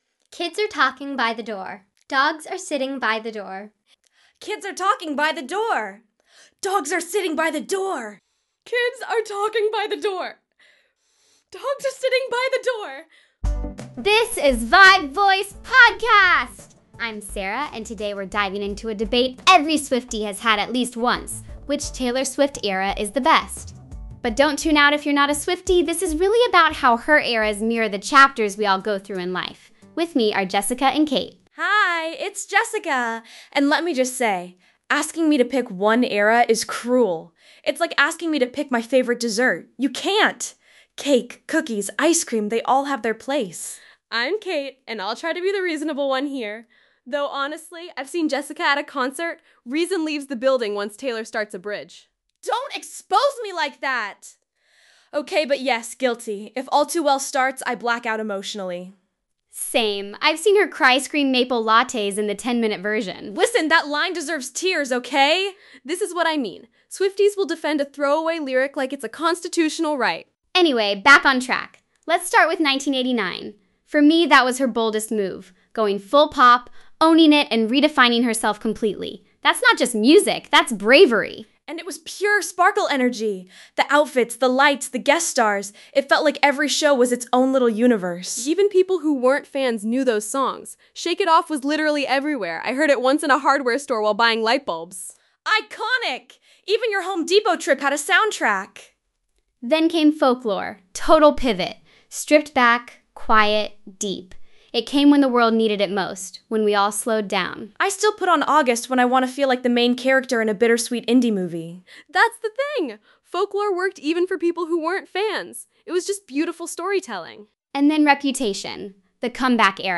I fed three 15 second audio clips with three different voices which you can hear little snippets in the beginning, along with a podcast transcript generated from ChatGPT, and got this AI slop. It’s just a one shot run with no editing. The quality isn’t quite at NotebookLM’s level, but it’s still pretty cool to see open-source TTS can achieve this locally.